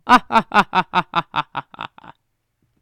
villain_human_laugh_comedic
Category 🤣 Funny
evil laughter villain sound effect free sound royalty free Funny